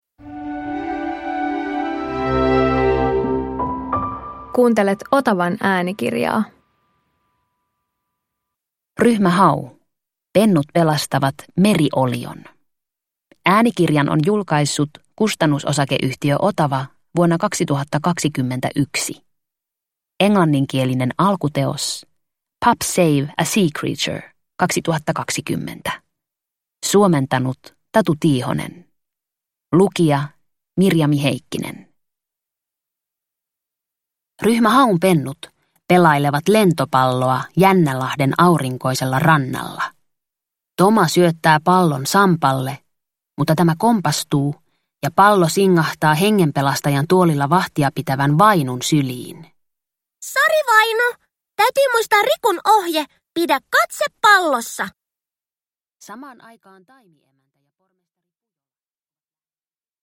Ryhmä Hau - Pennut pelastavat meriolion – Ljudbok – Laddas ner